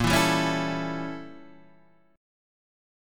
A7/A# chord